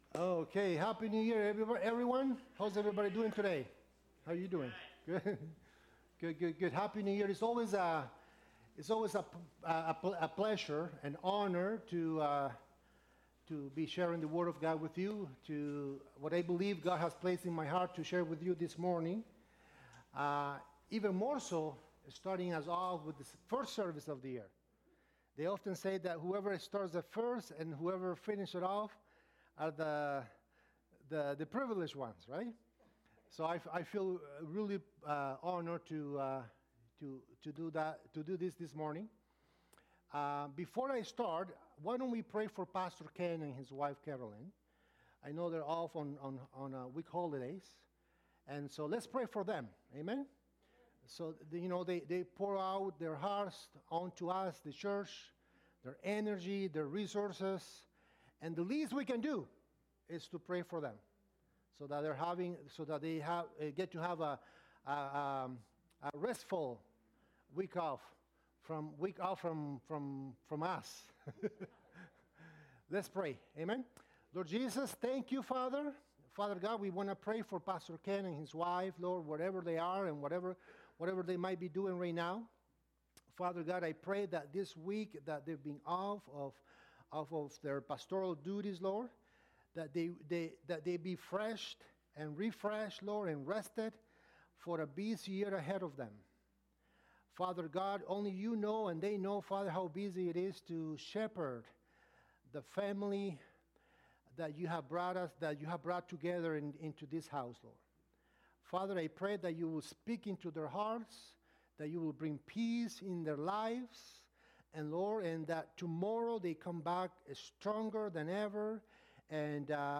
A Transformational Conversation